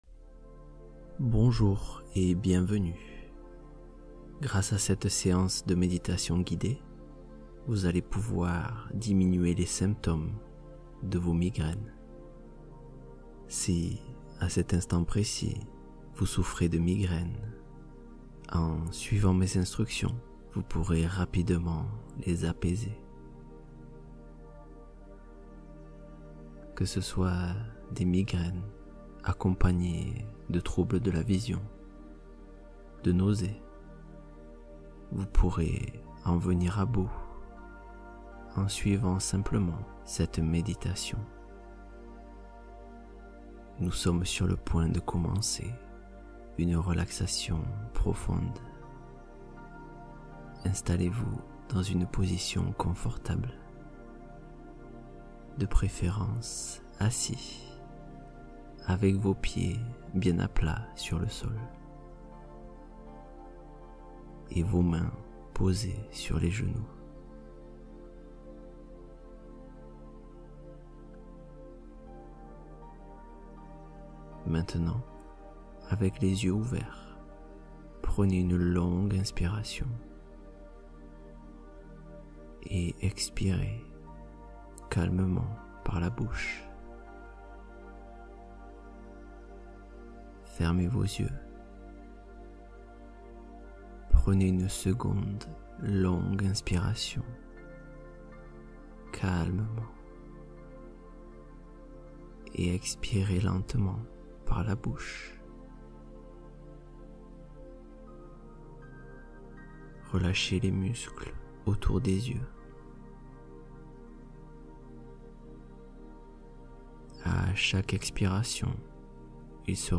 Méditation guidée pour soulager les migraines